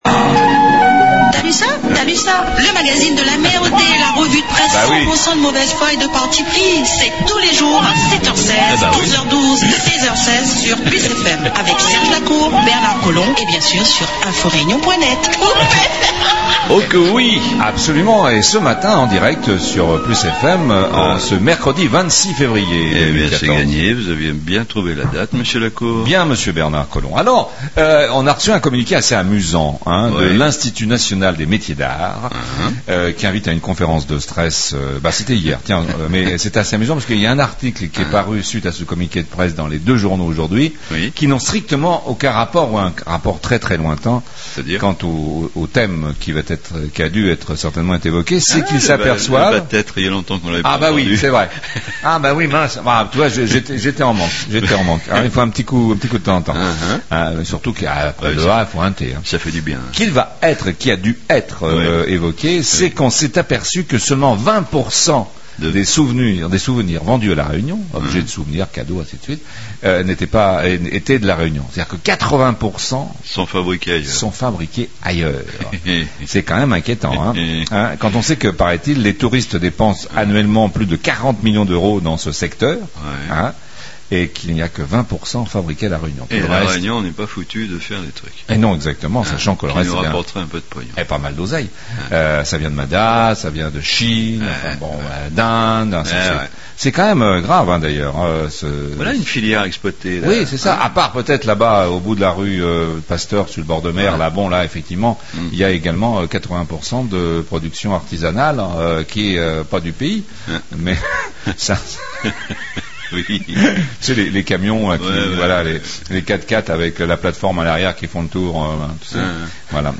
REVUE DE PRESSE Mercredi 26 Février 2014 .... et plein d'autres sujets dans le THALUSSA du jour